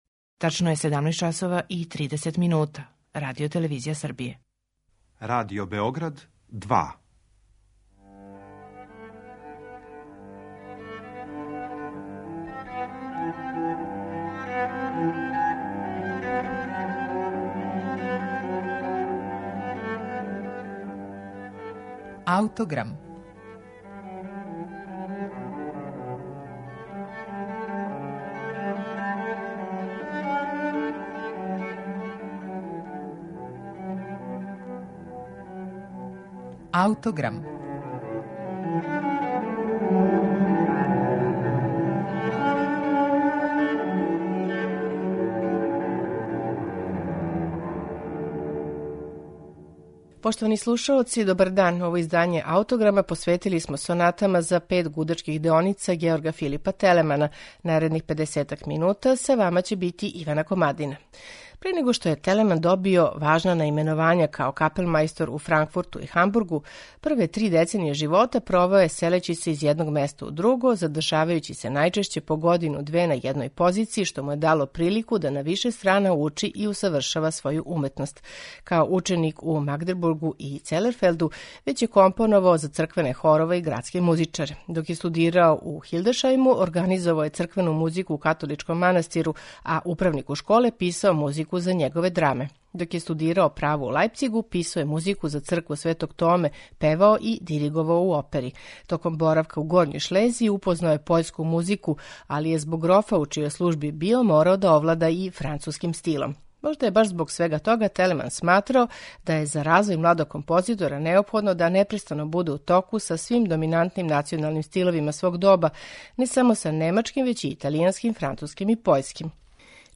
Сонате за гудаче Георга Филипа Телемана
Четири сонате за пет гудачких деоница
Четири сонате за пет гудачких деоница Георга Филипа Телемана слушаћете у интерпретацији чланова ансамбла Freiburger Barockorchester Consort .